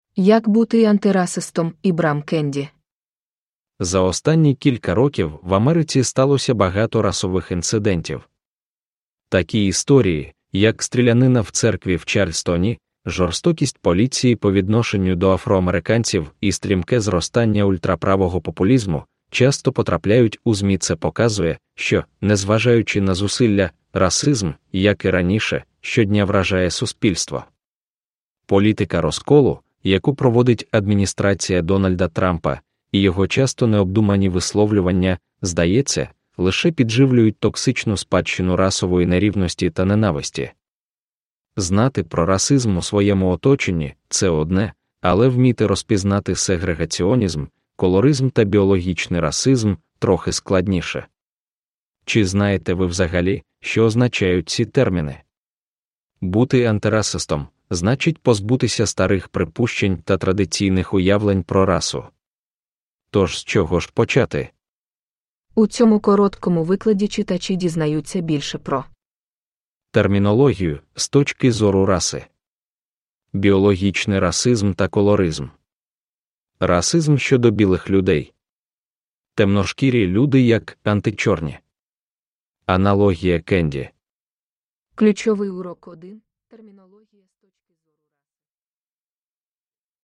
How to be an Antiracist – Ljudbok – Laddas ner
Uppläsare: Reedz Audiobooks